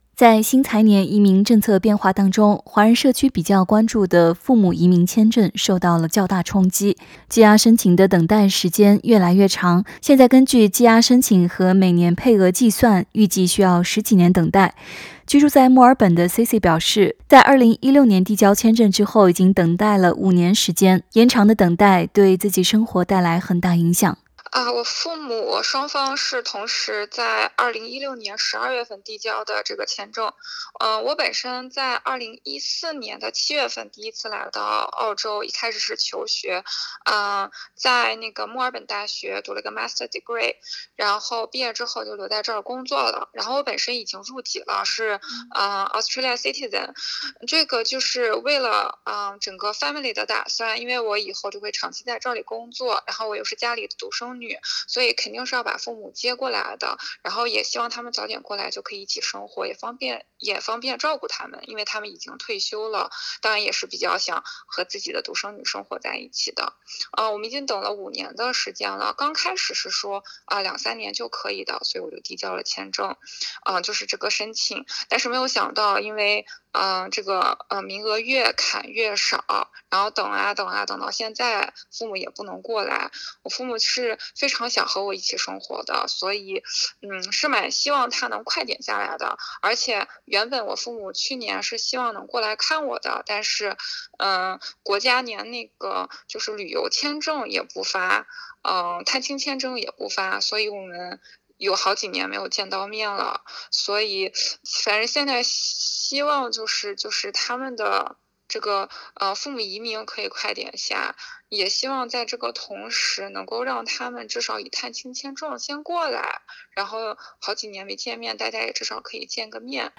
parents_visa_interview_final_mix.mp3